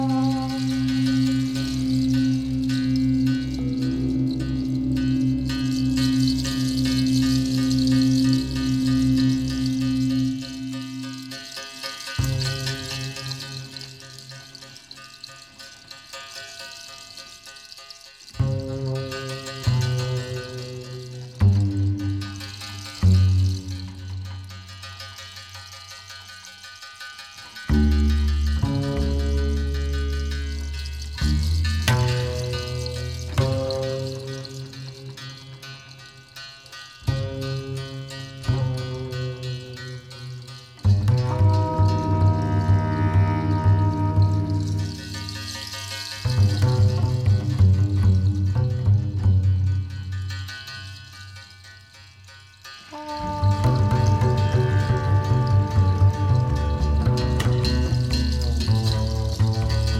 Pharoah Sandersを彷彿とさせる、生々しくタフでありながら優美なサックス。